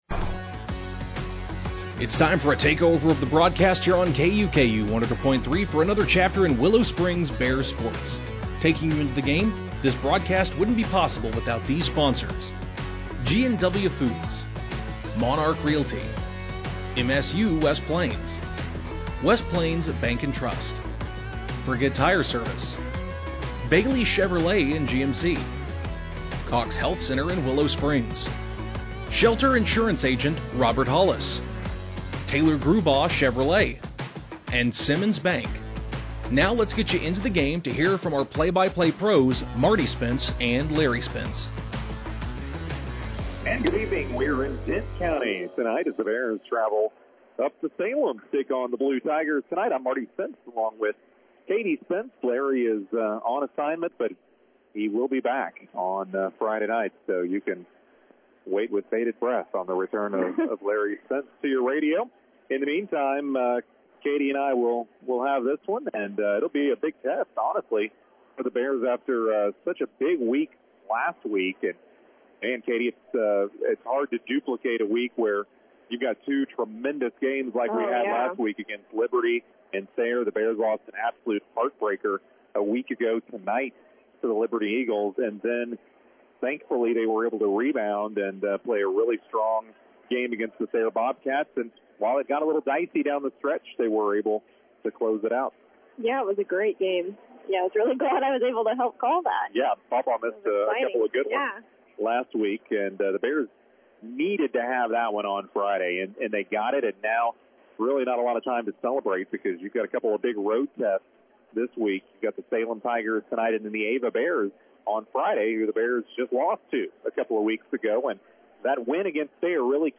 Game Audio Below: Salem won the tip to start the ball game and missed their first shot and got an over-the-back foul to start the game.